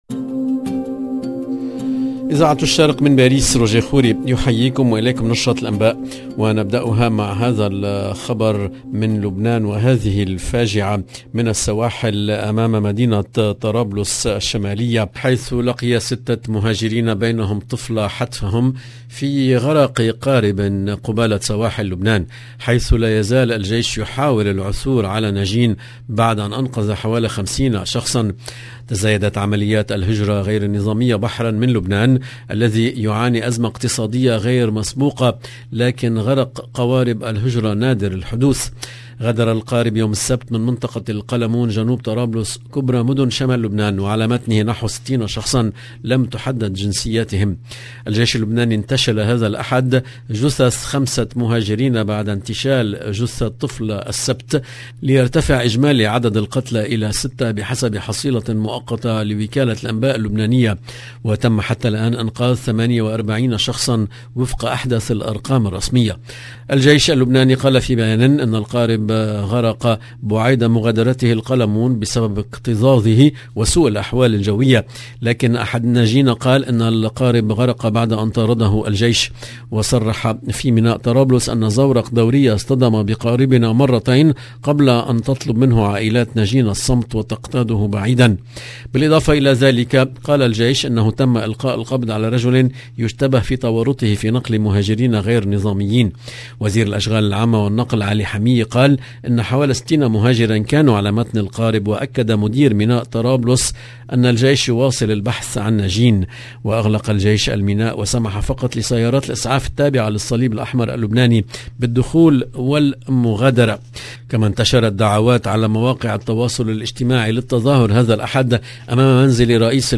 LE JOURNAL DU SOIR EN LANGUE ARABE DU 24/04/22